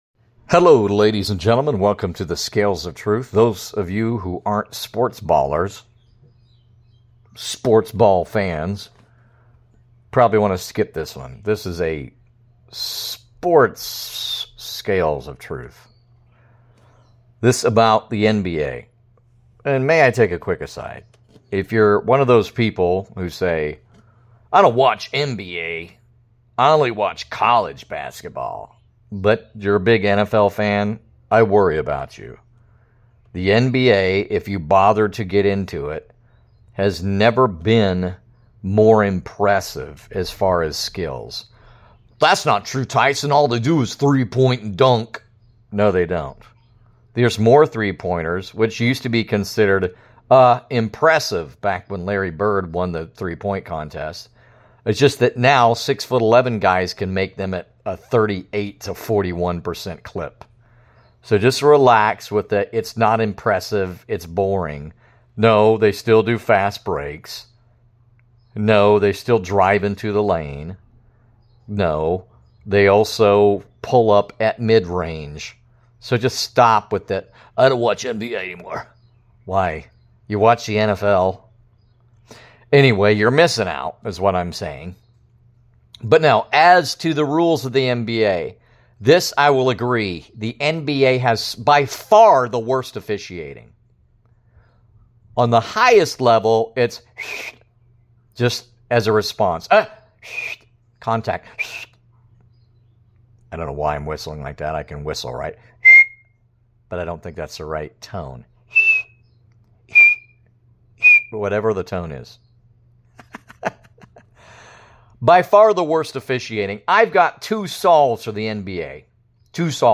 A very rare "Sports Rant of Truth."